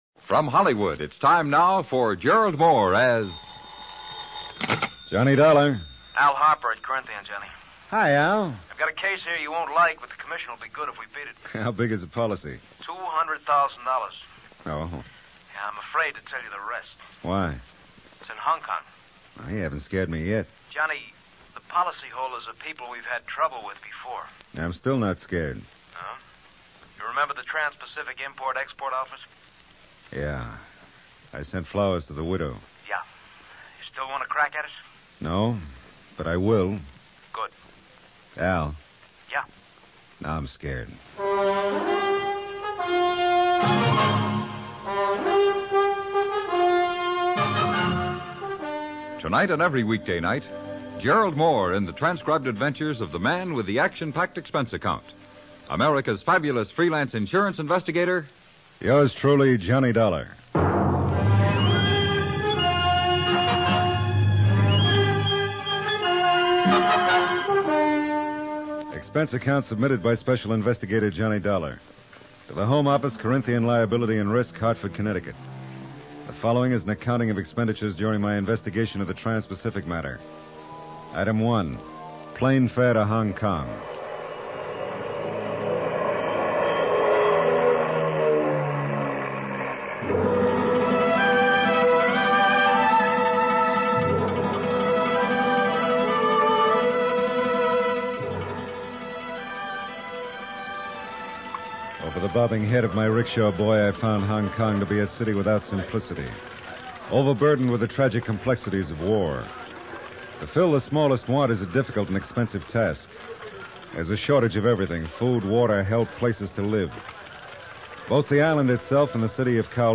Yours Truly, Johnny Dollar Radio Program, Starring Gerald Mohr